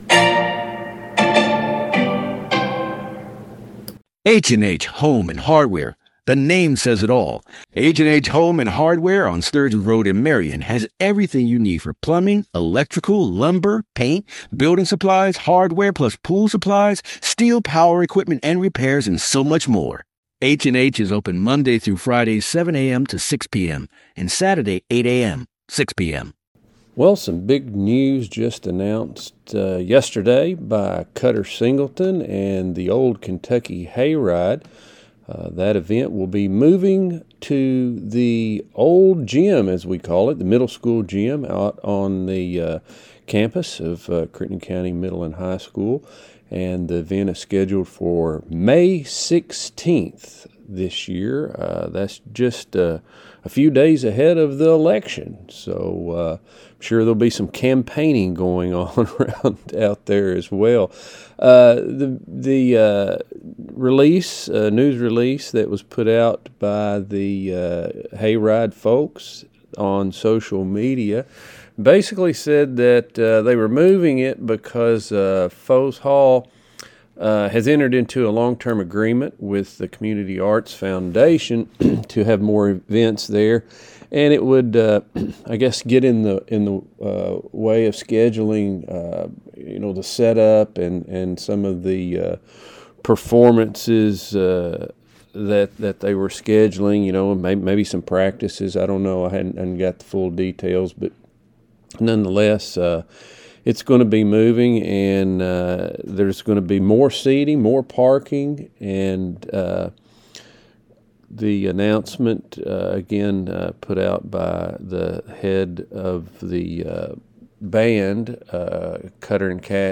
News | Sports | Interviews